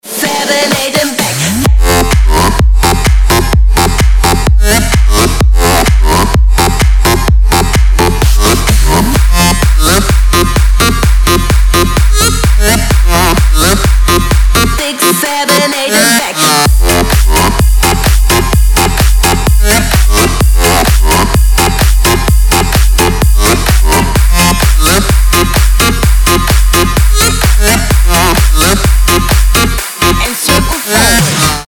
• Качество: 320, Stereo
dance
electro house
Крутой басистый мотивчик